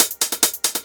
Fricky Hat Loop.wav